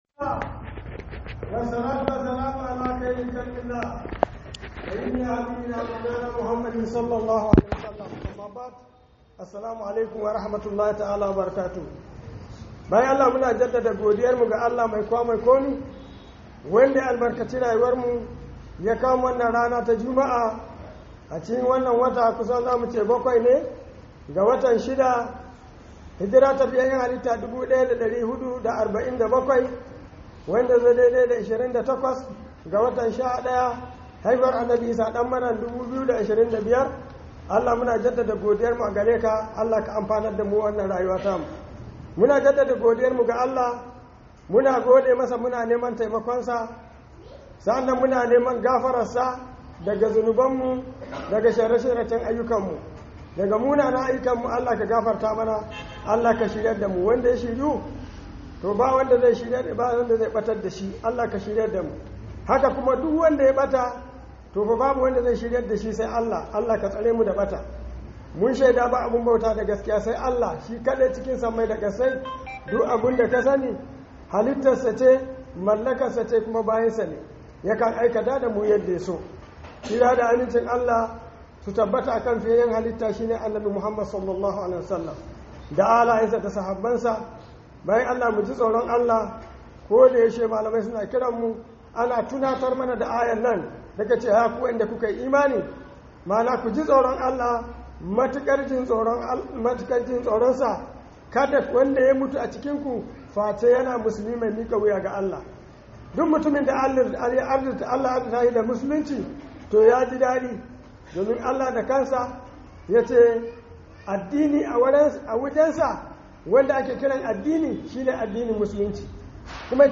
Khudubar Sallar Juma'a